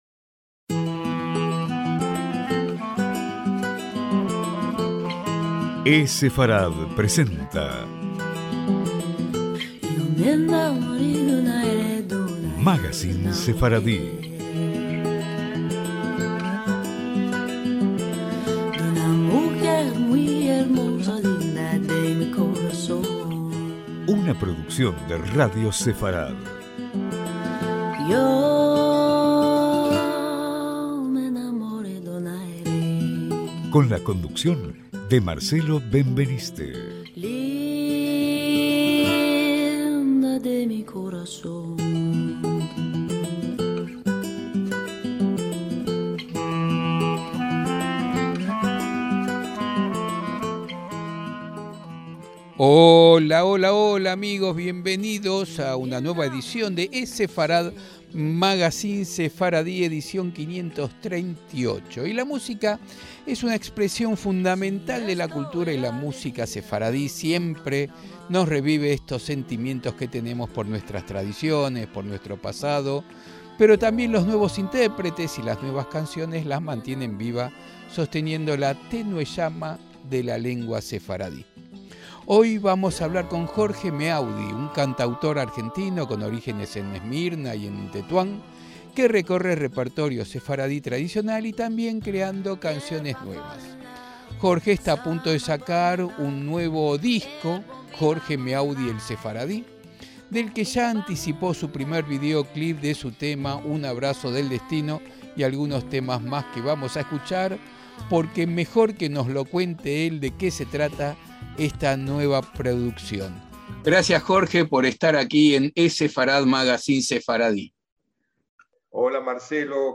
ESEFARAD: MAGACÍN SEFARDÍ - En el eSefarad Magacín Sefardí de esta semana vamos a hablar con